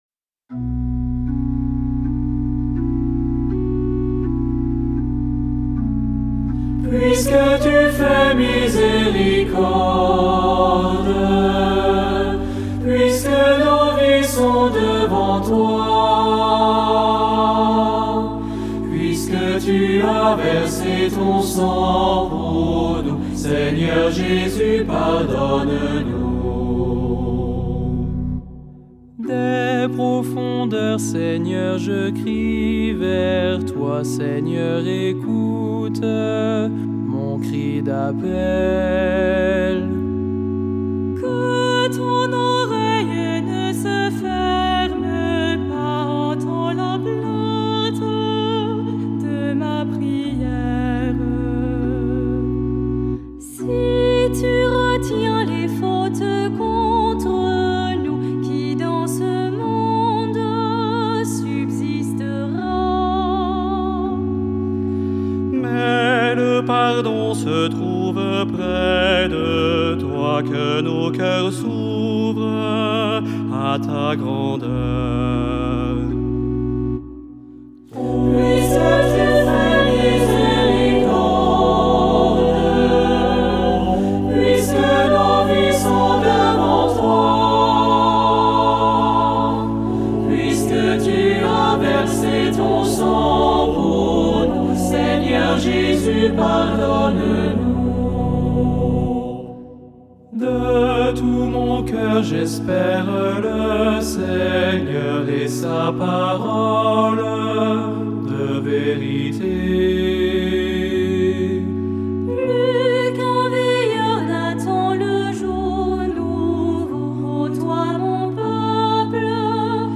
Le chant de cette semaine!